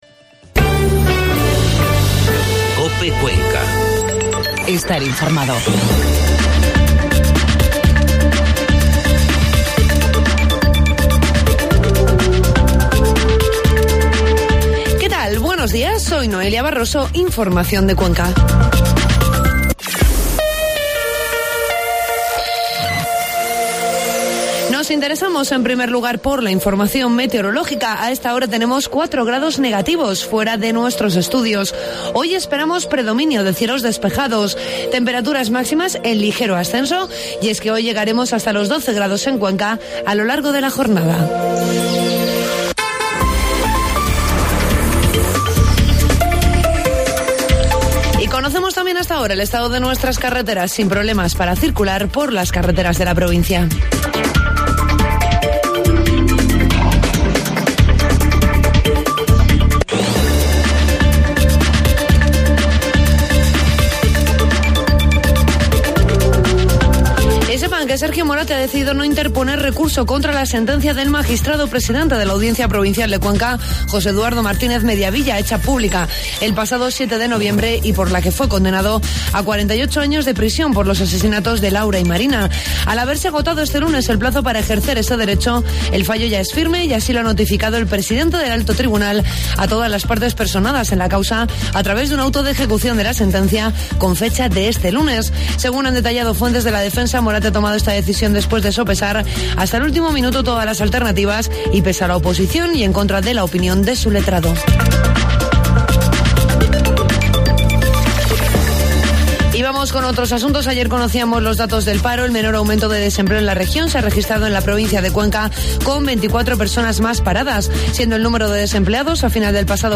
Informativo matinal COPE Cuenca 5 de diciembre